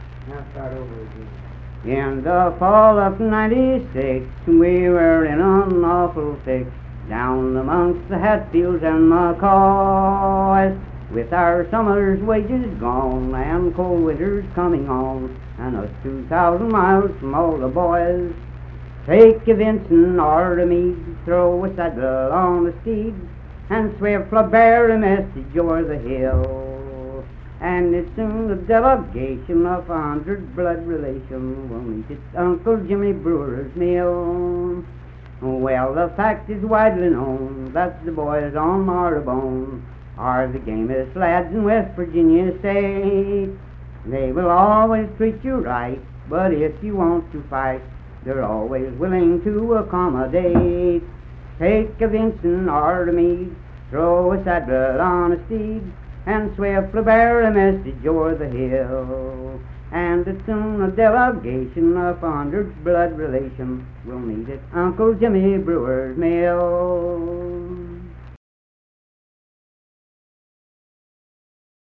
Unaccompanied vocal music
Political, National, and Historical Songs
Voice (sung)
Mingo County (W. Va.), Kirk (W. Va.)